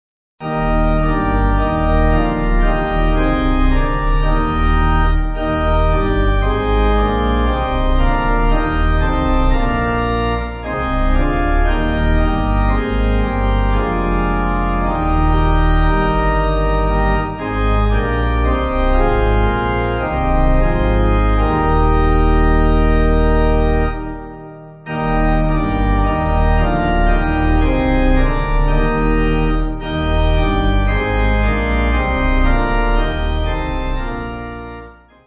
(CM)   5/Eb